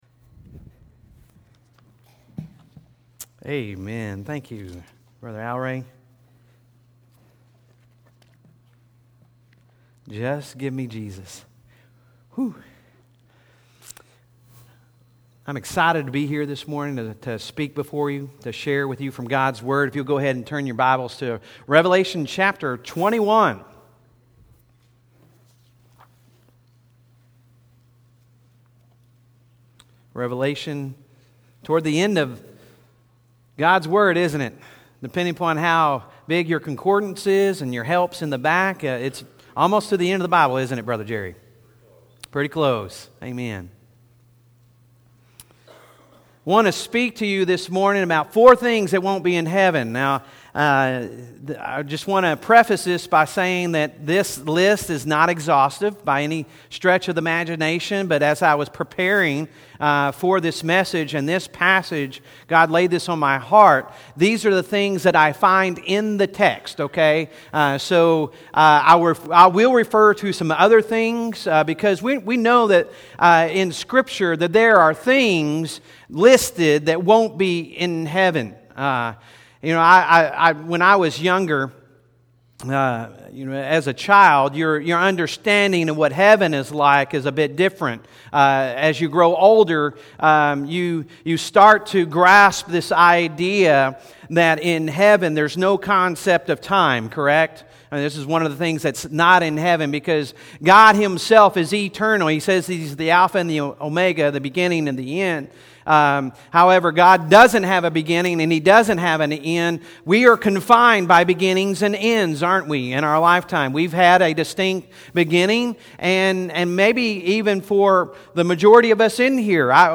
Sunday Sermon October 13, 2019